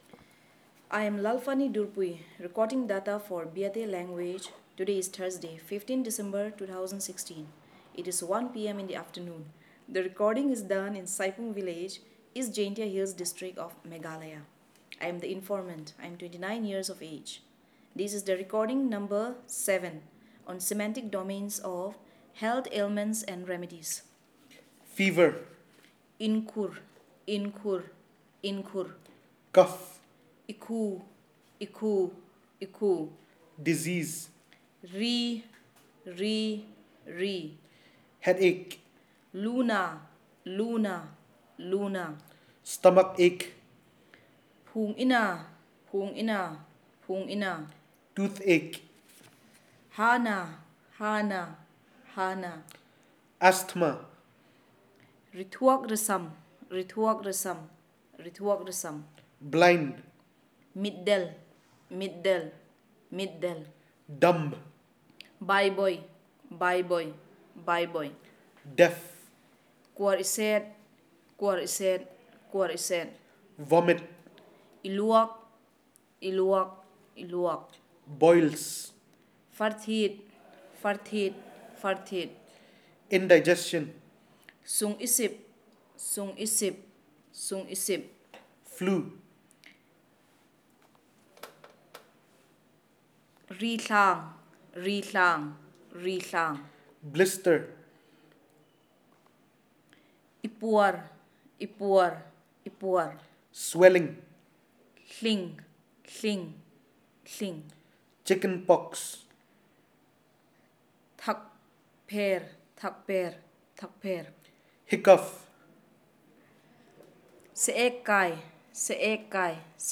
Elicitation of words about health ailments and remedies